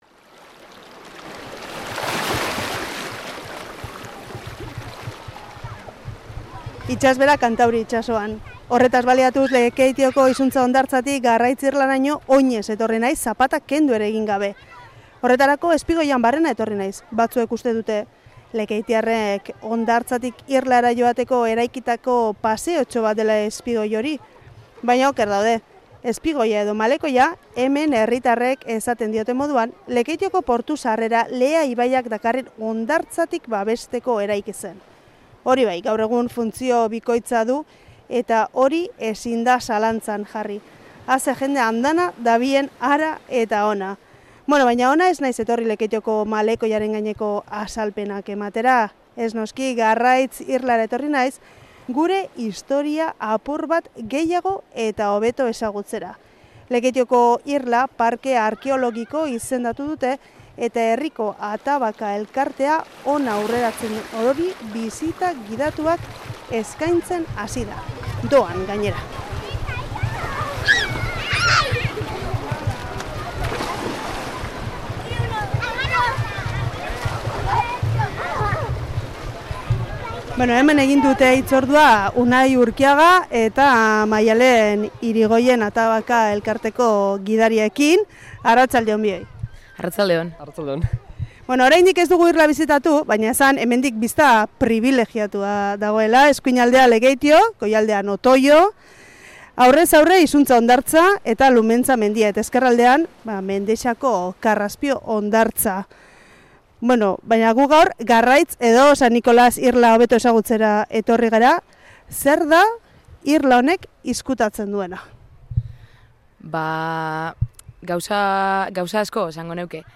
Audioa: Lekeitioko Atabaka elkartea herriko Garraitz irlan doako bisita gidatuak eskaintzen ari da. Eusko Jaurlaritzak irla parke arkeologiko izendatu zuen eta Arantzadi Zientzia elkarteak indusketak egiten jarraitzen du. Amaraunak bisita gidatu horietako bat egin du irlak zer kontatzen duen jakiteko.